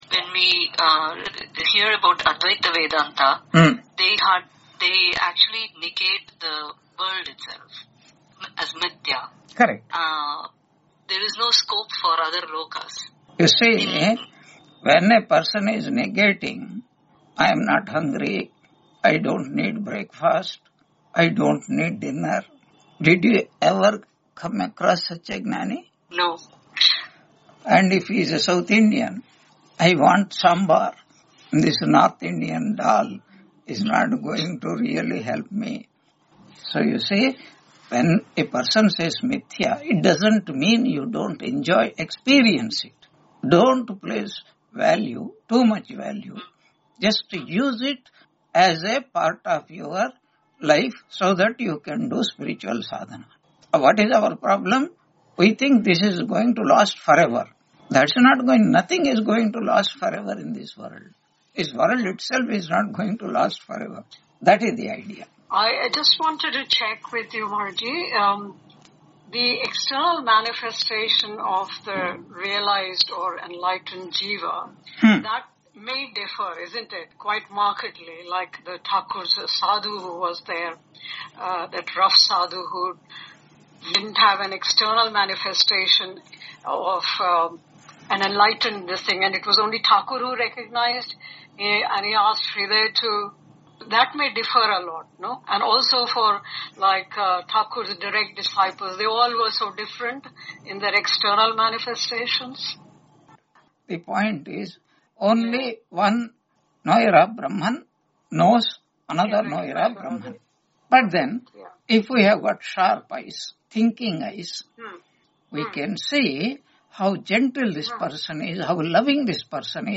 Taittiriya Upanishad Lecture 89 Ch2 7-8 on 28 January 2026 Q&A - Wiki Vedanta